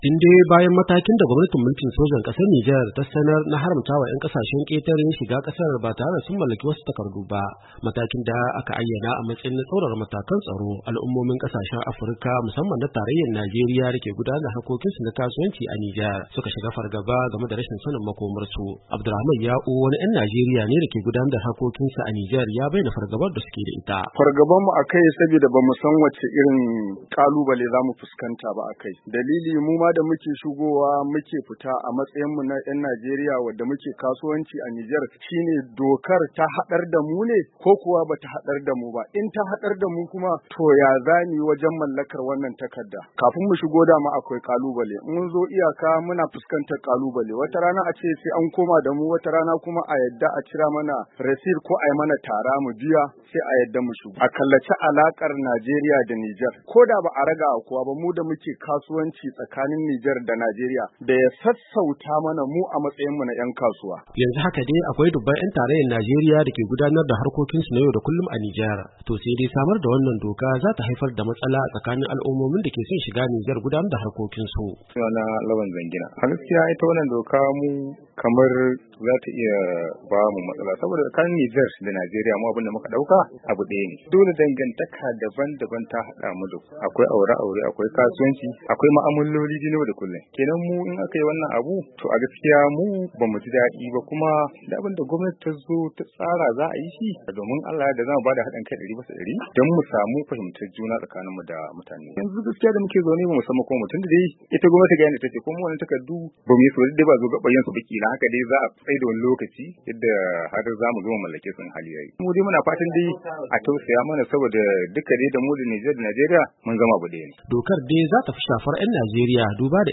AGADEZ, NIGER —